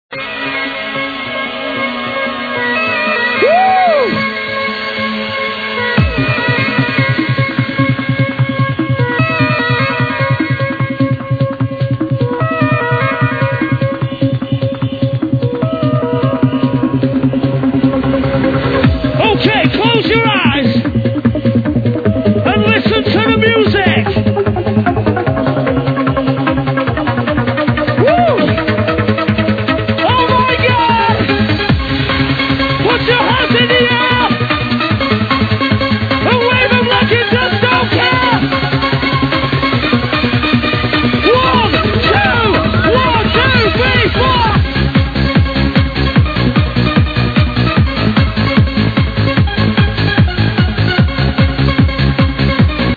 w/beat speeded up.